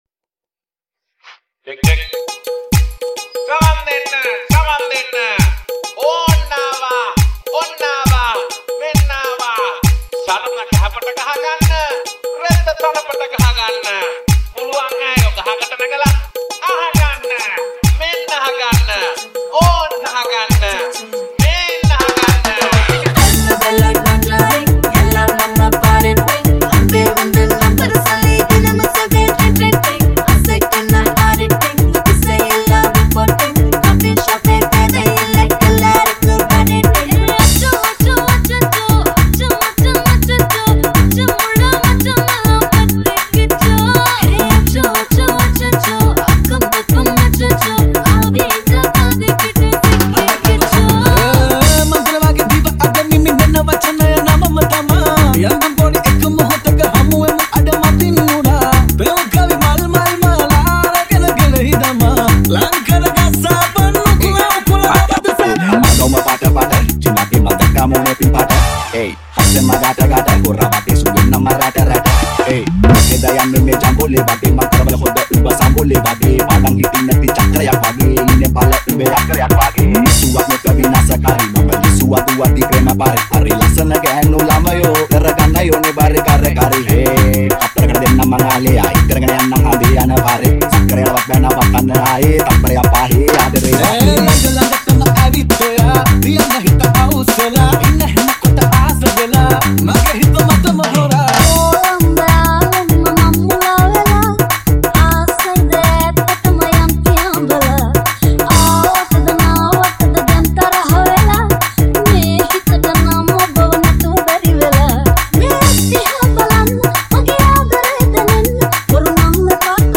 High quality Sri Lankan remix MP3 (11).